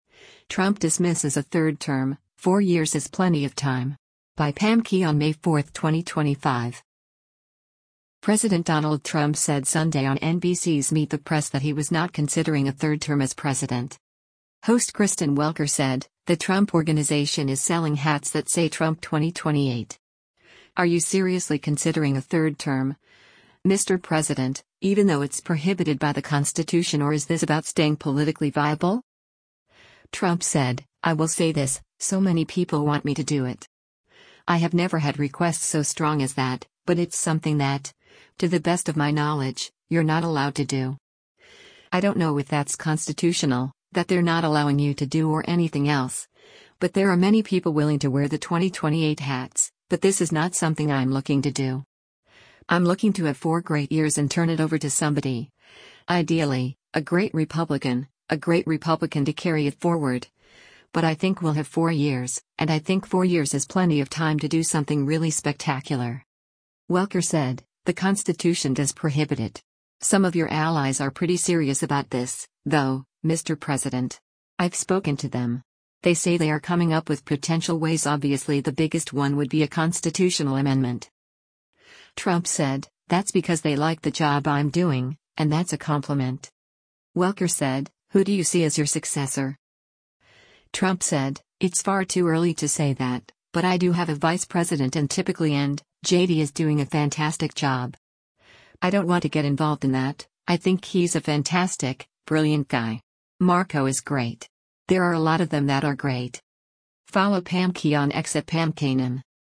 President Donald Trump said Sunday on NBC’s “Meet the Press” that he was not considering a third term as president.